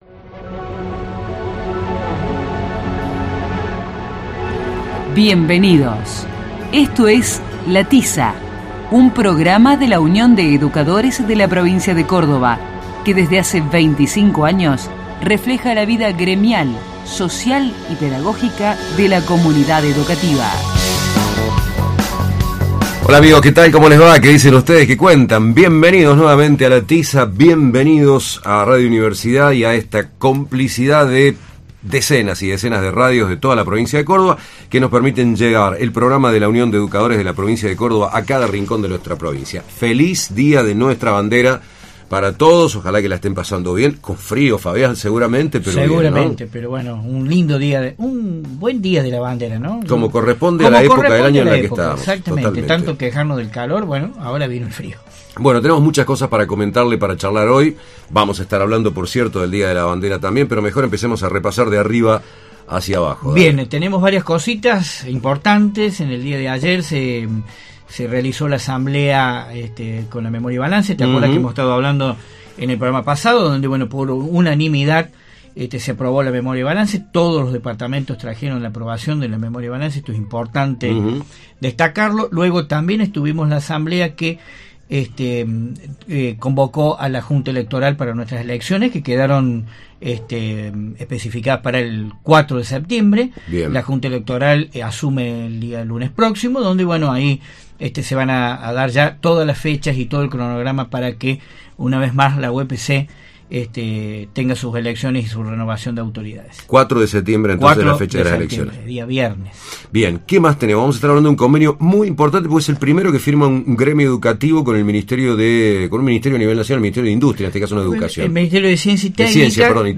El Sindicato cuenta con el programa de radio "La Tiza", en el cual se reflejan las temáticas gremiales, educativas y pedagógicas que interesan a los y las docentes.
El programa se emite los sábados de 12 a 13 hs por radio Universidad (AM 580) y de 16 a 17 hs por Más que música (FM 102.3).